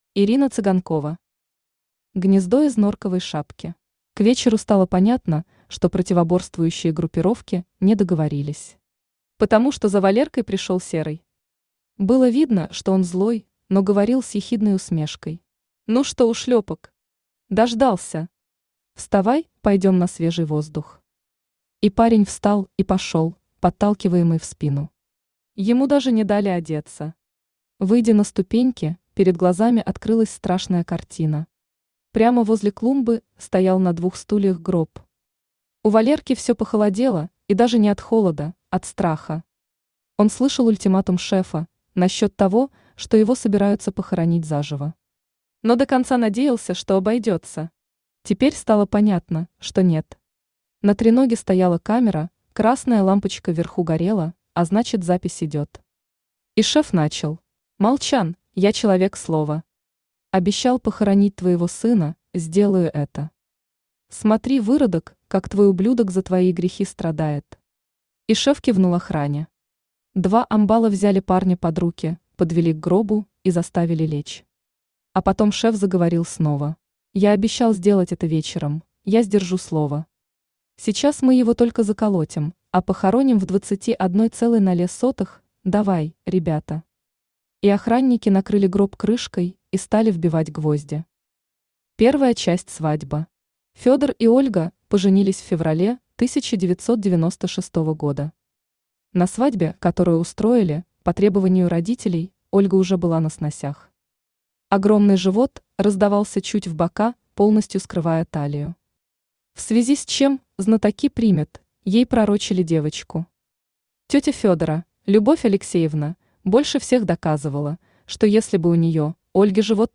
Аудиокнига Гнездо из норковой шапки | Библиотека аудиокниг
Aудиокнига Гнездо из норковой шапки Автор Ирина Михайловна Цыганкова Читает аудиокнигу Авточтец ЛитРес.